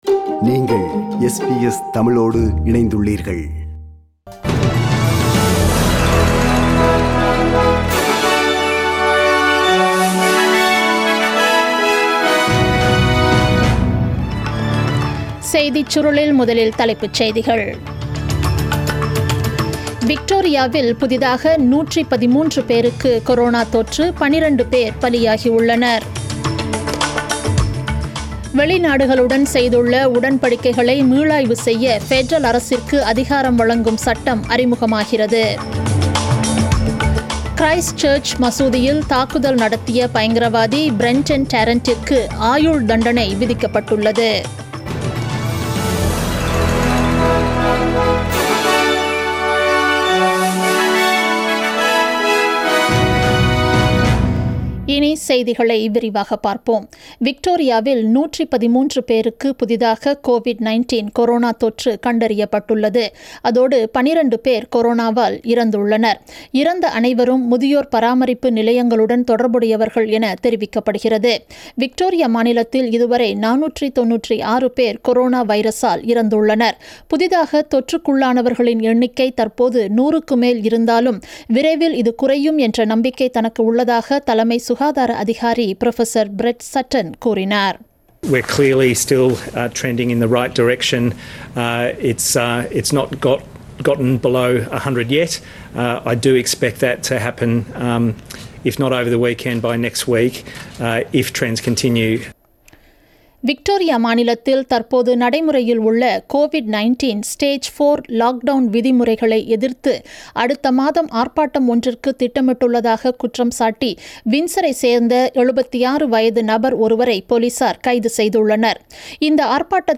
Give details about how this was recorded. The news bulletin broadcasted on 28 August 2020 at 8pm.